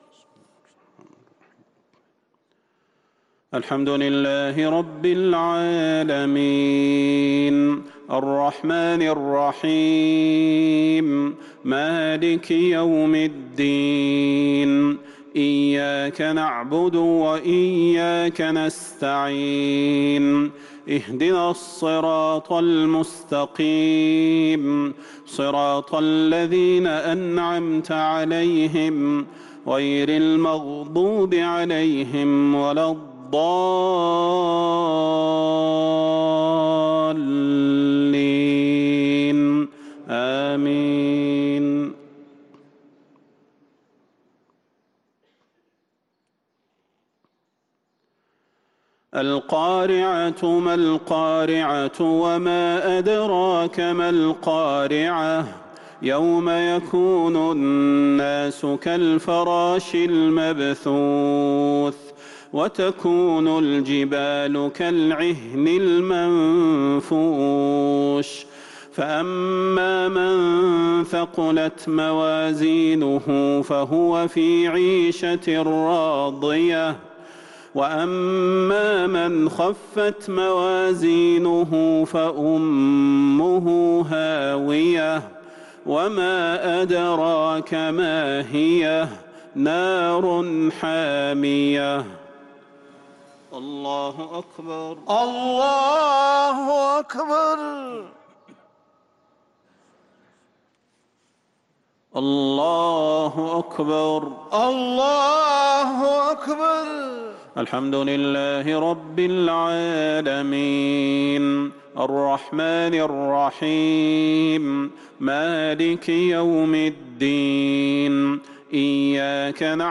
مغرب الجمعة 1-8-1443هـ سورتي القارعة و التكاثر | Maghrib prayer from Surat AlQari'ah and al-Takathur 4-3-2022 > 1443 🕌 > الفروض - تلاوات الحرمين